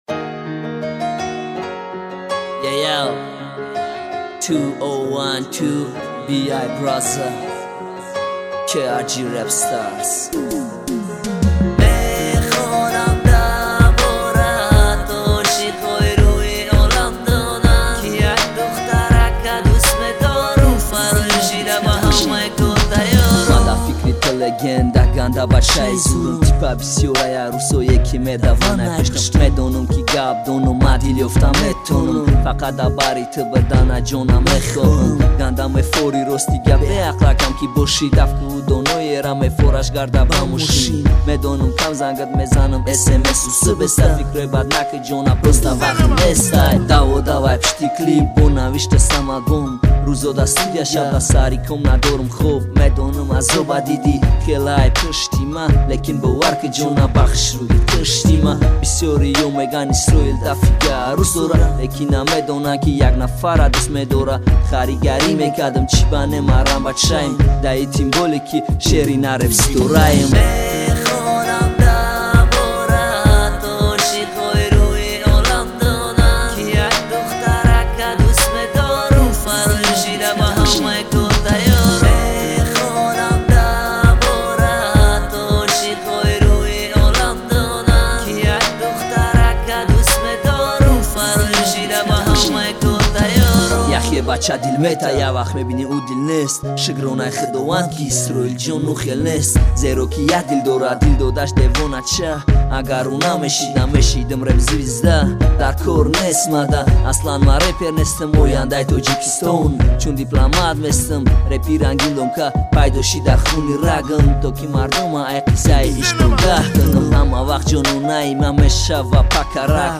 таджикский реп
Tajik rap